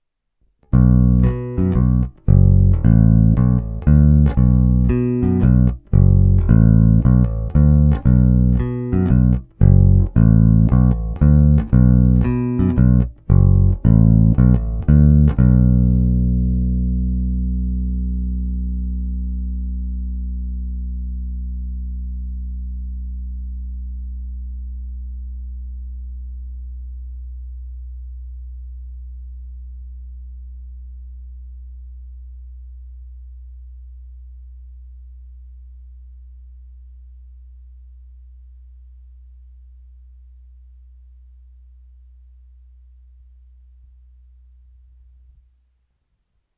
Nahrál jsem jen jednu ukázku na porovnání s původním snímačem, a to rovnou do zvukovky.
Ukázka Squier snímač